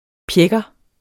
Udtale [ ˈpjεgʌ ]